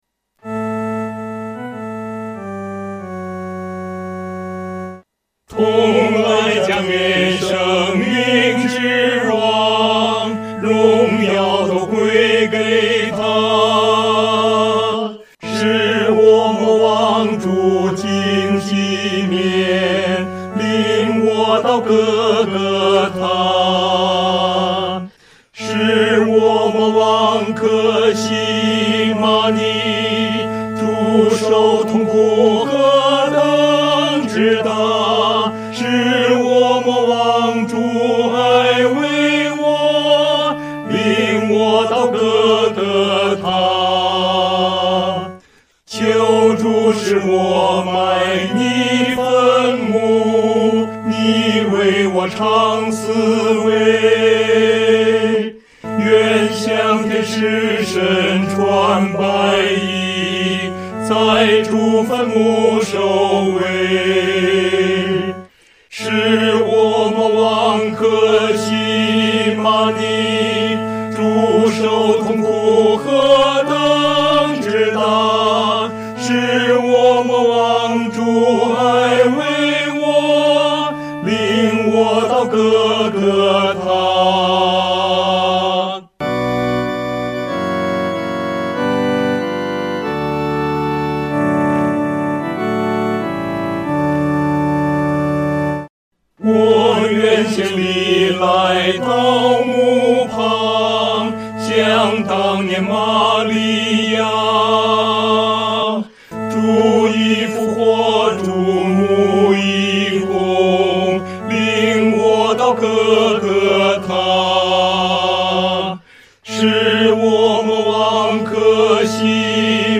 合唱
四声 下载
且具有进行曲的风味。